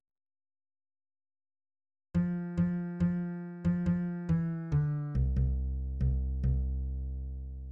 << %{ \new Staff \with {instrumentName = "S A" shortInstrumentName = "S A"} << \set Staff.midiMinimumVolume = #0.3 \set Staff.midiMaximumVolume = #0.7 \set Score.currentBarNumber = #1 \bar "" \tempo 4=70 \time 4/4 \key bes \major \new Voice = "s1" \relative c '' { \voiceOne bes2 a bes a bes4 a c a bes2 a } \new Voice = "s2" \relative c '{ \voiceTwo ees2 d ees d ees4 d f d ees2 d } >> \new Lyrics \lyricsto "s1" {\set fontSize = #-2 s' brent s' brent und- zer shte- tl bre -nt de } %} %{ \new Staff \with {instrumentName = "A" shortInstrumentName = "A"} << \set Staff.midiMinimumVolume = #0.3 \set Staff.midiMaximumVolume = #0.5 \set Score.currentBarNumber = #1 \bar "" \tempo 4=70 \time 4/4 \key bes \major \new Voice = "a1" \fixed c ' { \voiceOne g1 g g g } \new Voice = "a2" \fixed c ' { \voiceTwo d1 d d d } >> \new Lyrics \lyricsto "a1" {\set fontSize = #-2 } \repeat volta 2 %} %{ \new Staff \with {instrumentName = "T" shortInstrumentName = "T"} << \set Staff.midiMinimumVolume = #0.3 \set Staff.midiMaximumVolume = #0.5 \set Score.currentBarNumber = #1 \bar "" \tempo 4=70 \time 4/4 \key bes \major \new Voice = "t1" \fixed c ' { \voiceOne g1 g g g } \new Voice = "t2" \fixed c ' { \voiceTwo d1 d d d } >> \new Lyrics \lyricsto "t1" {\set fontSize = #-2 } \repeat volta 2 %} \new Staff \with {midiInstrument = #"acoustic bass" instrumentName = "B" shortInstrumentName = "B"} << \set Staff.midiMinimumVolume = #14.7 \set Staff.midiMaximumVolume = #15.9 \set Score.currentBarNumber = #1 \bar "" \tempo 4=70 \time 6/8 \key c \major \clef bass \new Voice = "b1" \relative c { \voiceOne r2 r8 f8 f8 f8. f16 f8 e d c,16 c8. c8 c4.